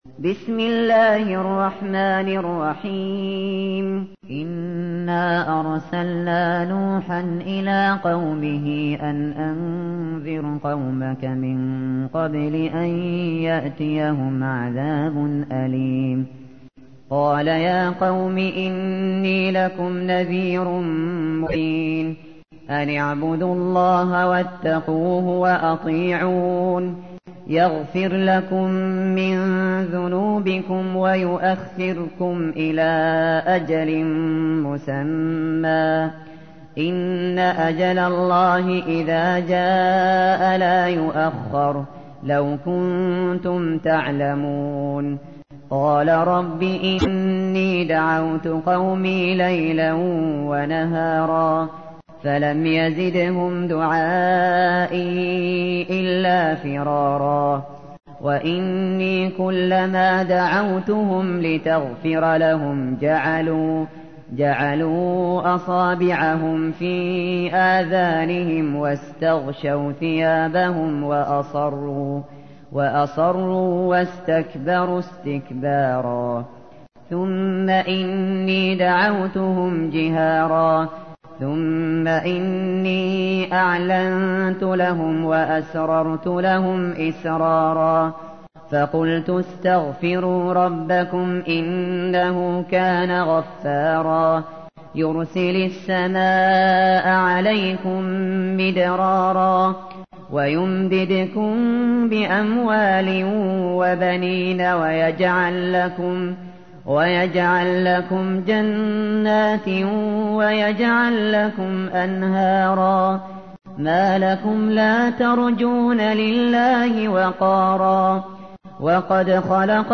تحميل : 71. سورة نوح / القارئ الشاطري / القرآن الكريم / موقع يا حسين